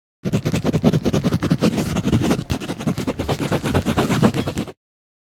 writing.ogg